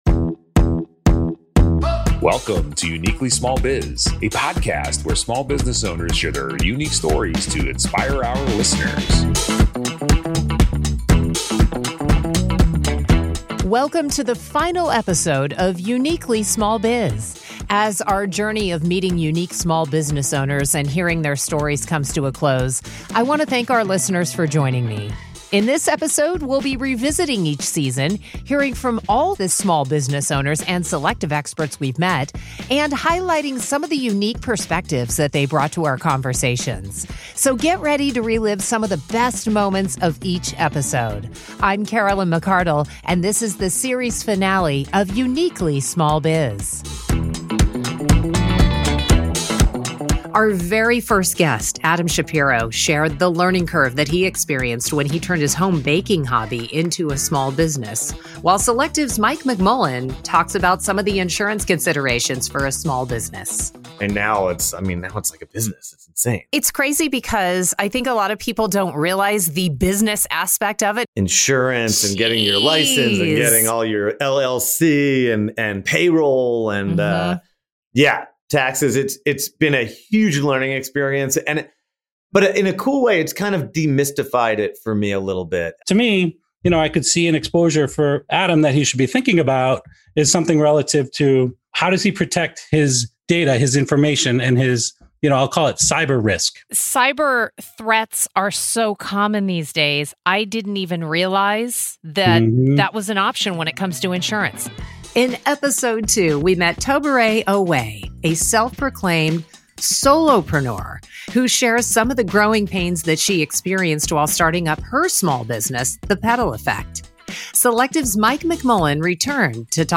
Each episode closes with helpful tips from series sponsor Selective Insurance on how small businesses can grow.